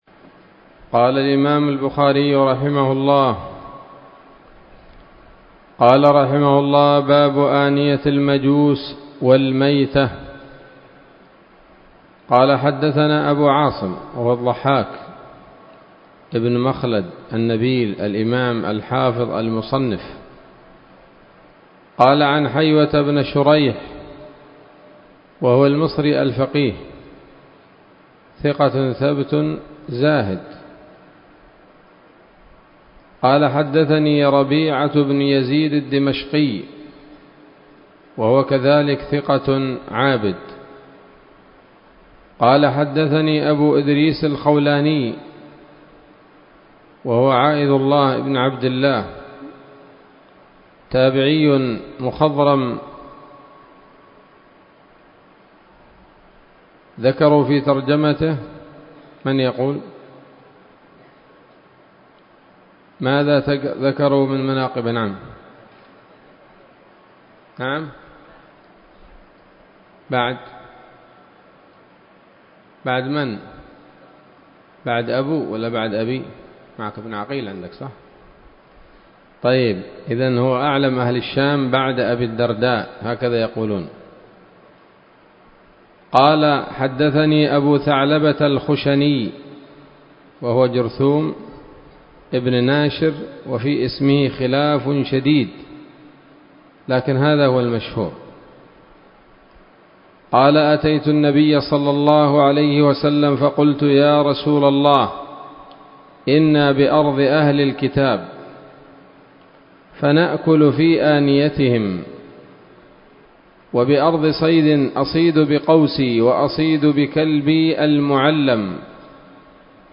الدرس الثاني عشر من كتاب الذبائح والصيد من صحيح الإمام البخاري